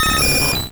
Cri de Roucoups dans Pokémon Rouge et Bleu.